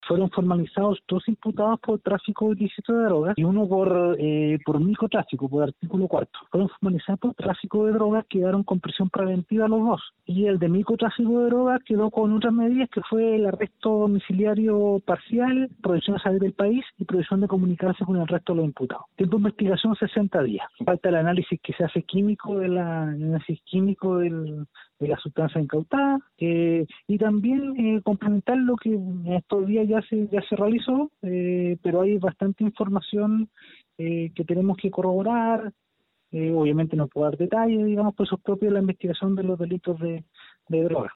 Así lo confirmó el fiscal jefe de Ancud Javier Calisto, señalando que el tribunal acogió buena parte de los requerimientos del ministerio público para con este caso, por los delitos de tráfico los dos primeros y microtráfico el tercer implicado.